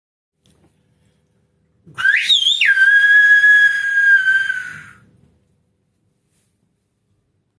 Així, una paraula xiulada com /GAYÍYA/